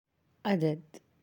(adad)